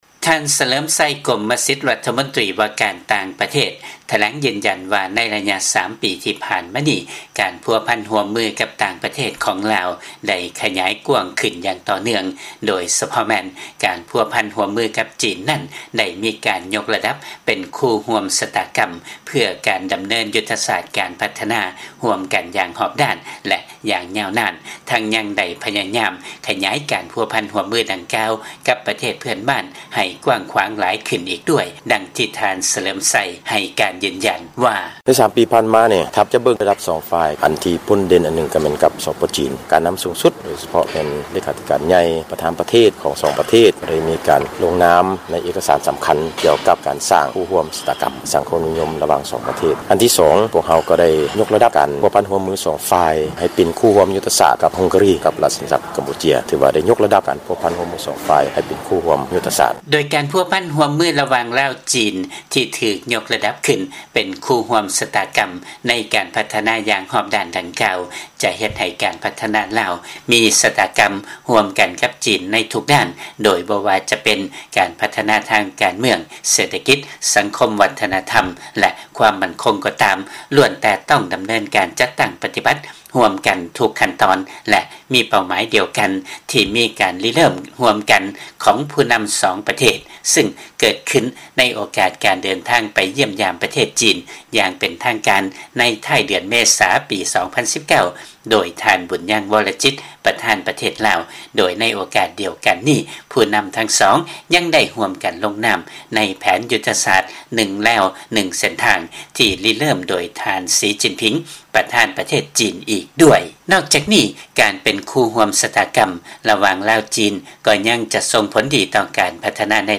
ມີລາຍງານຈາກ ບາງກອກ.